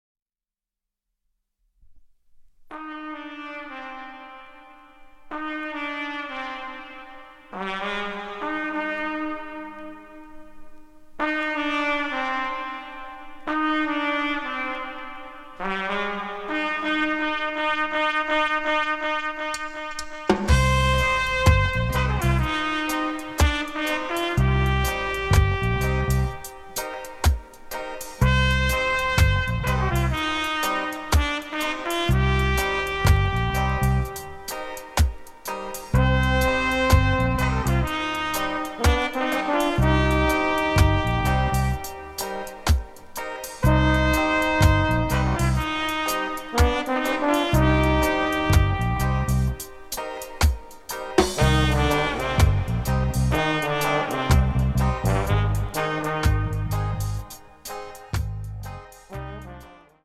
Bass
Trumpet
Trombone
Recorded Phenomenon Studio
Tokyo Sep. 1983